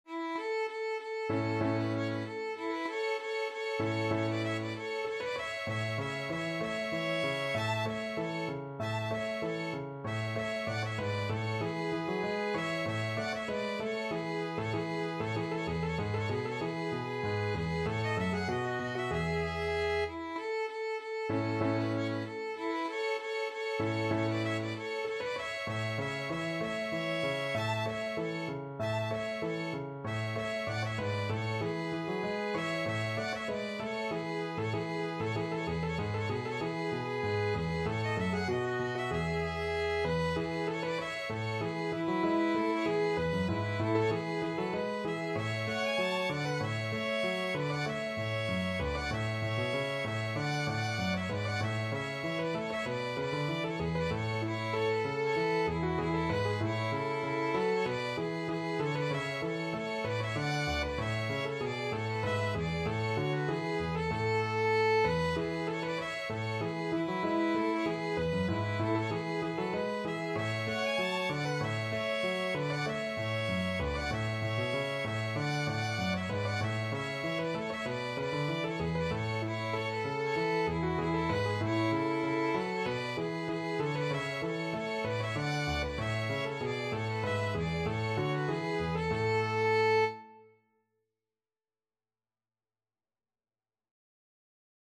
Free Sheet music for Violin Duet
Violin 1Violin 2Piano
A major (Sounding Pitch) (View more A major Music for Violin Duet )
= 96 Allegro (View more music marked Allegro)
4/4 (View more 4/4 Music)
Violin Duet  (View more Intermediate Violin Duet Music)
Classical (View more Classical Violin Duet Music)